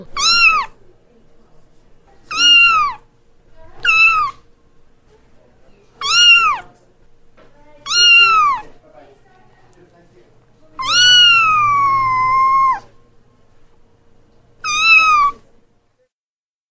Звук котенка, зовущего маму